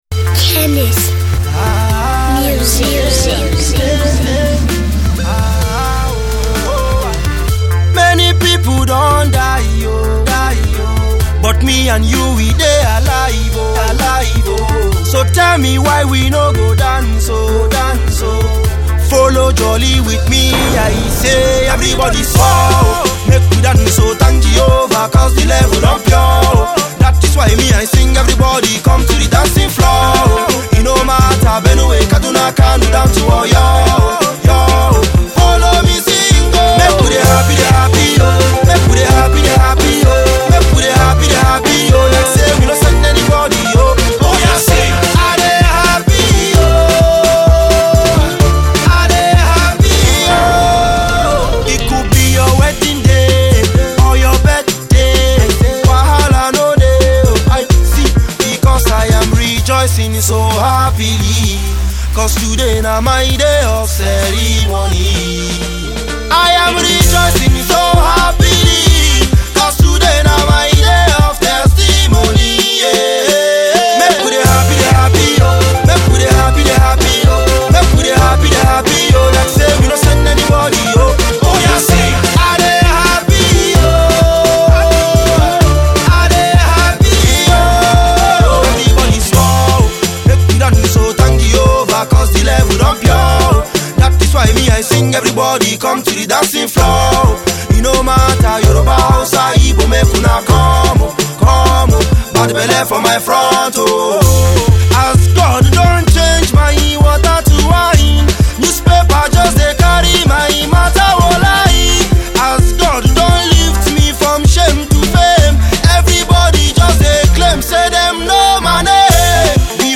feel good party track
the smooth voiced crooner